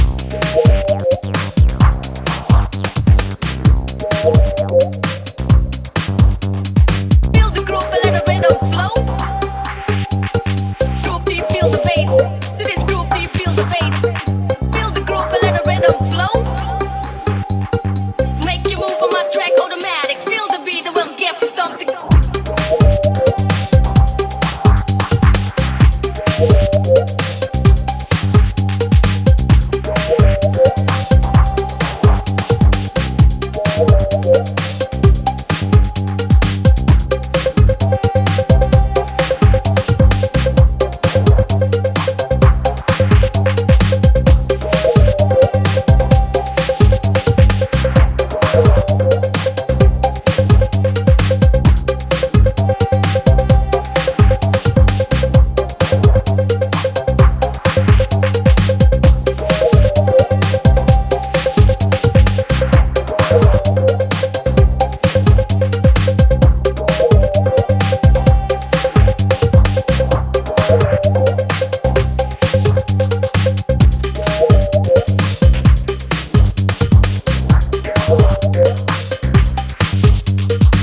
steeped in the raw essence of analog synthesis